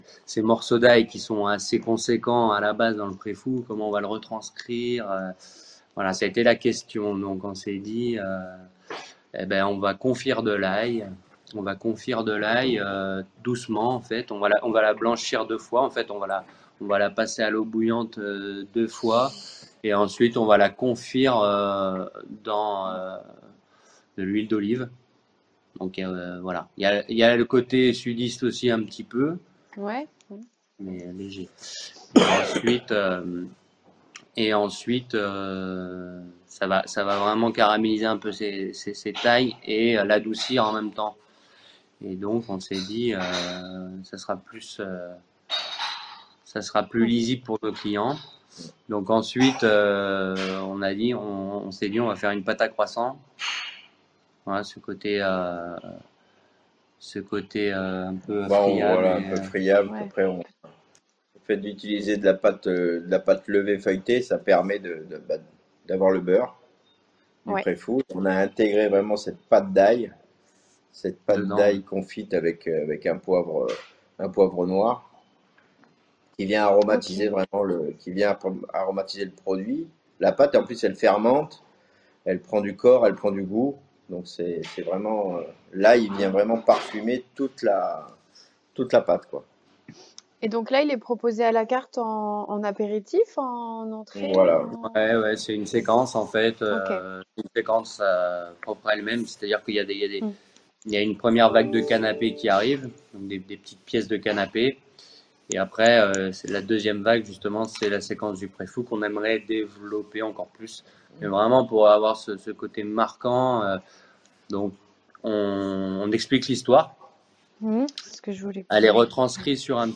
enregistrement audio d'échanges effectués en visio
Catégorie Témoignage